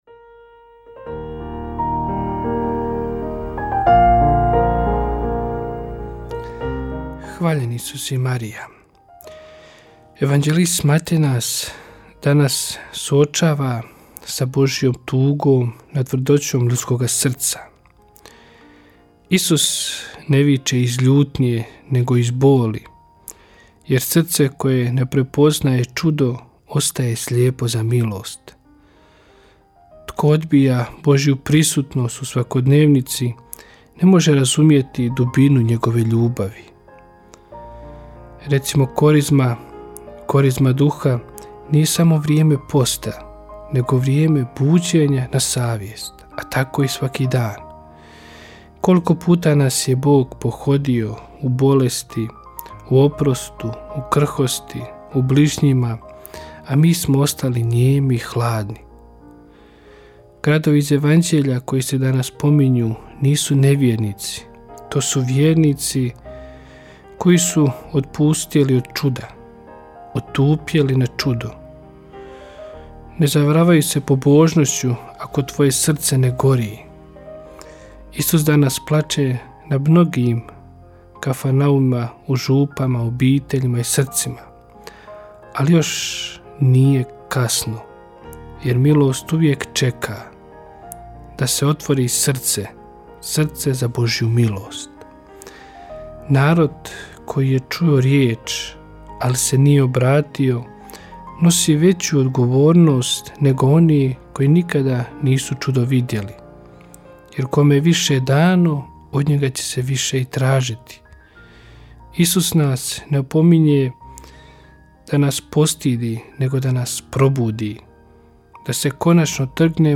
Kratku emisiju ‘Duhovni poticaj – Živo vrelo’ slušatelji Radiopostaje Mir Međugorje mogu čuti od ponedjeljka do subote u 3 sata i u 7:10. Emisije priređuju svećenici i časne sestre u tjednim ciklusima.